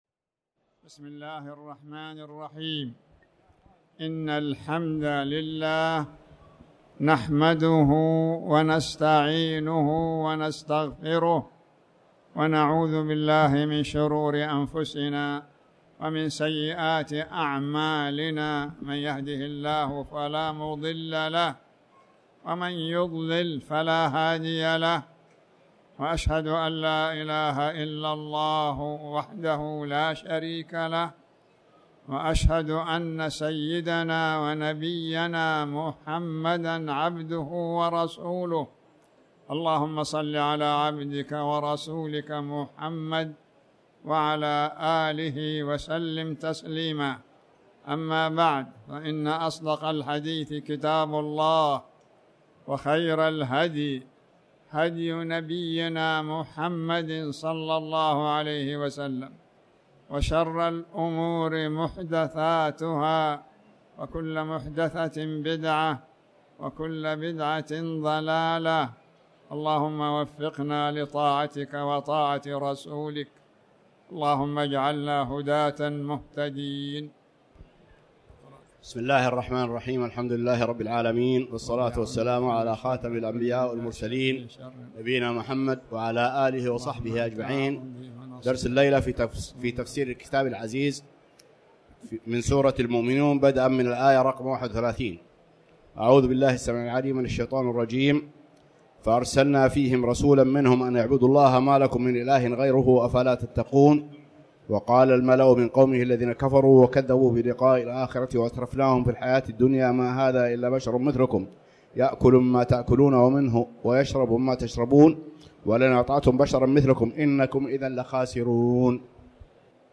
تاريخ النشر ٢٥ شوال ١٤٣٩ هـ المكان: المسجد الحرام الشيخ